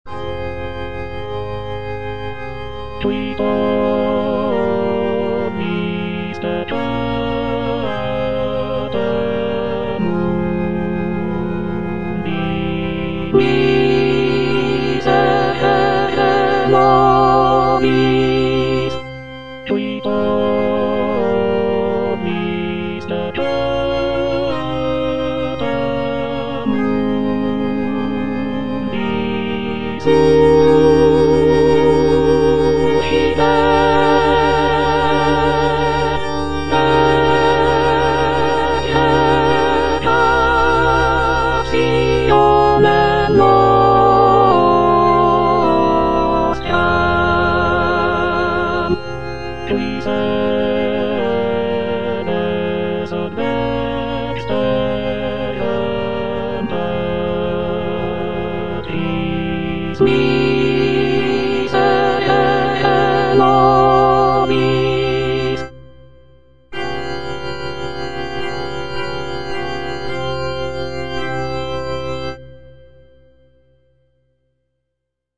T. DUBOIS - MESSE IN F Qui tollis - Soprano (Emphasised voice and other voices) Ads stop: auto-stop
It is a setting of the traditional Catholic Mass text in the key of F major. The piece is known for its lush harmonies, intricate counterpoint, and lyrical melodies.